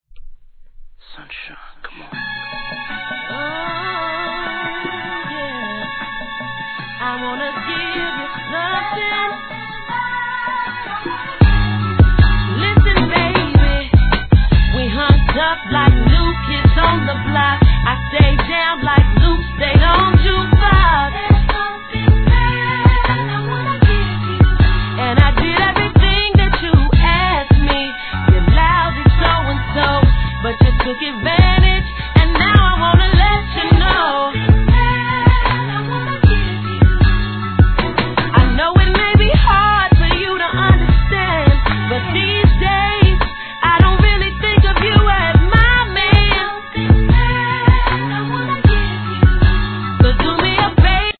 HIP HOP/R&B
ソウルネタが何ともキャッチーな好ミディアム♪